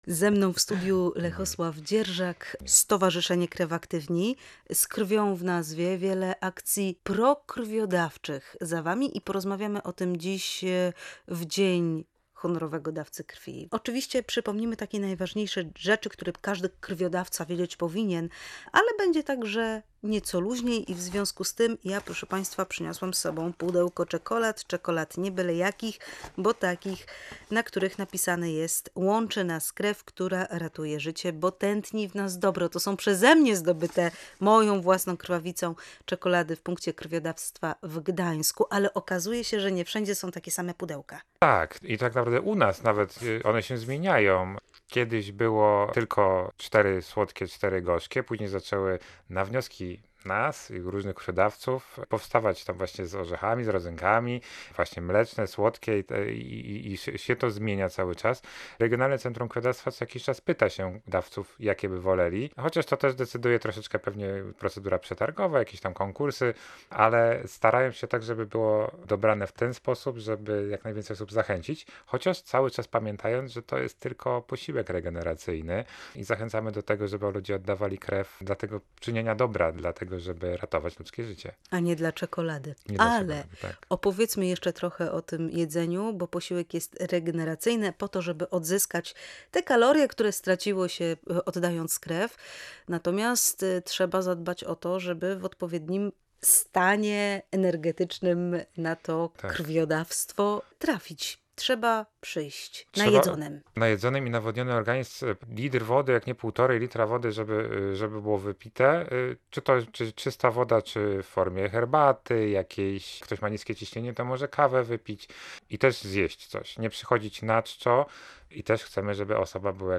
Nasz gość wytłumaczył między innymi, w jaki sposób należy przygotować się do oddania krwi.